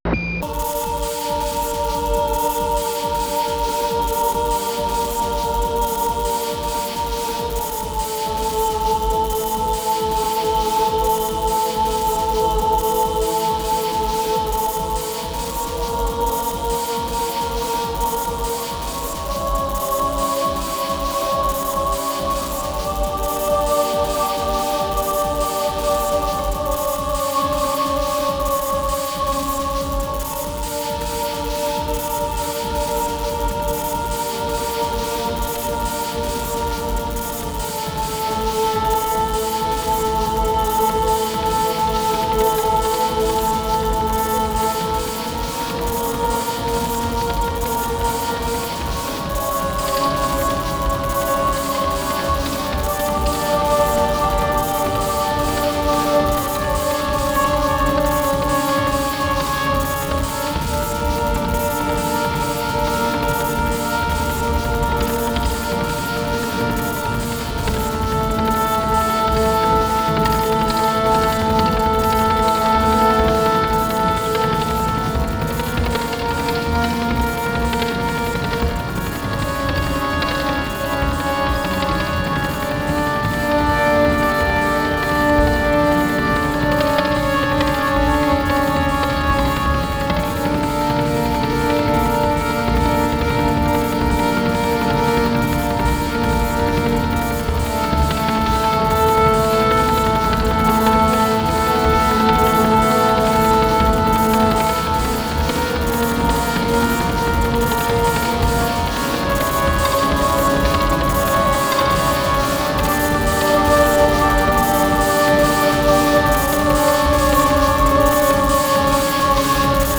合唱、
音声モーフィング、
オルガン、
ハードコア・ビート、
不整動パンニング、
非実存ギターによるパーカッシブ・タッピング、